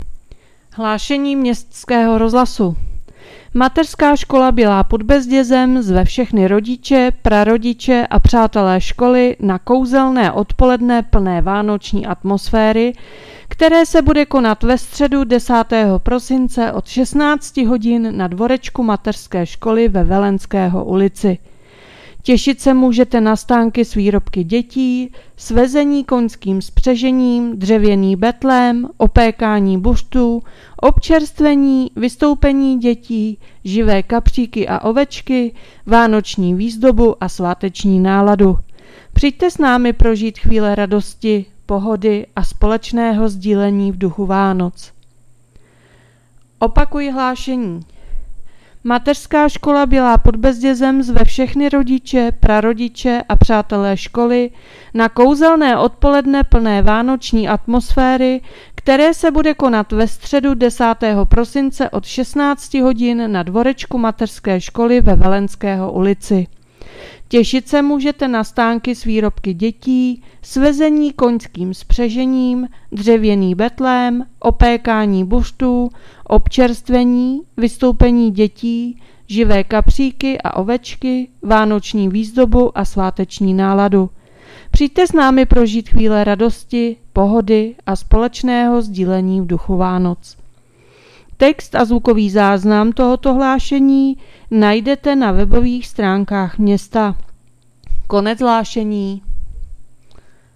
Hlášení městského rozhlasu 8.12.2025